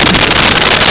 Sfx Crash Metal Short
sfx_crash_metal_short.wav